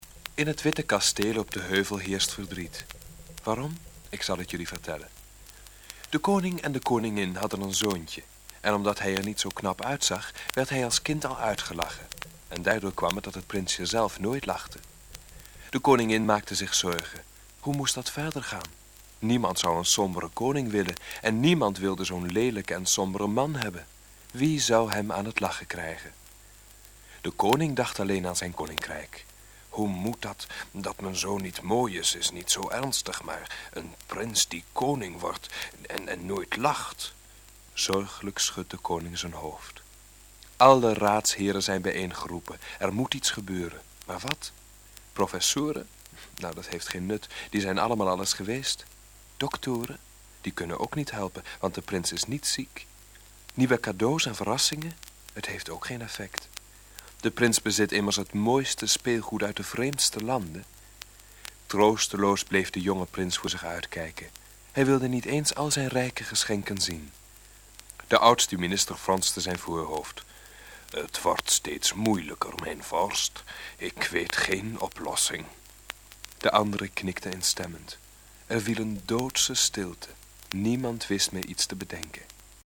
Vertellingen